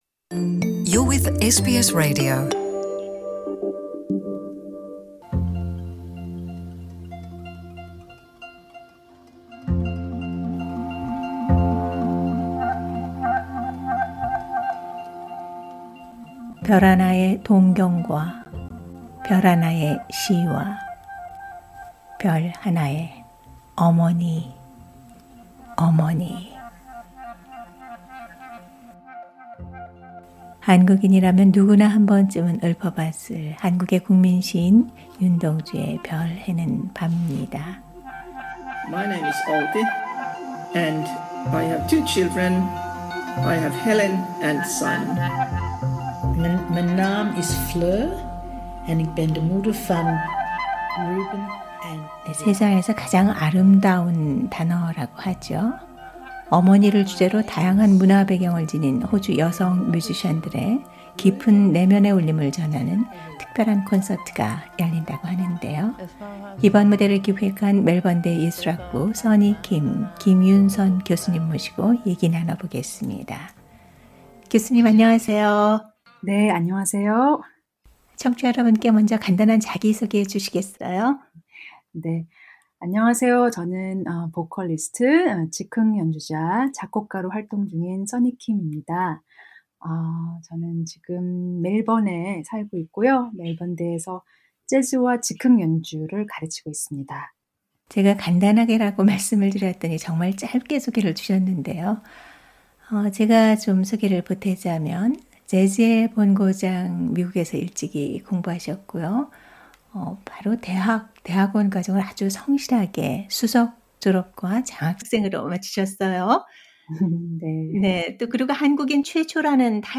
[인터뷰] 호주 현대·전통 그리고 한국의 소리